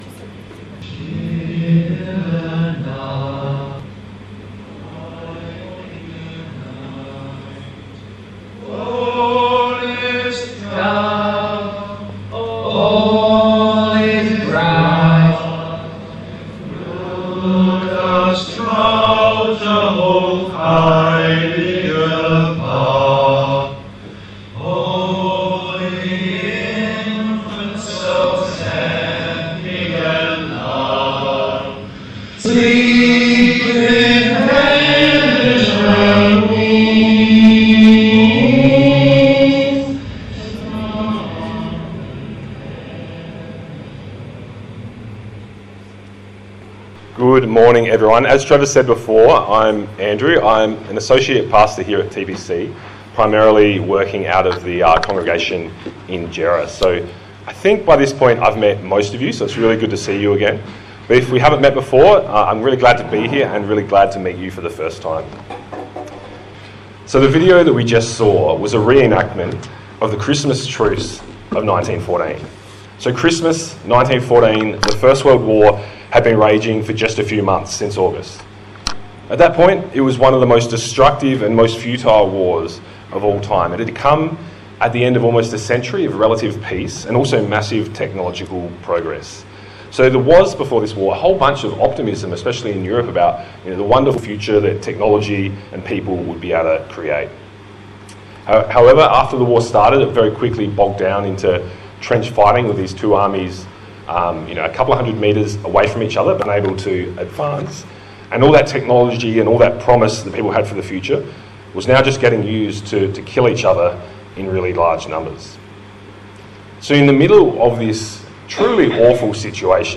A sermon in the Advent series 2024 on Carols
Service Type: Sunday Morning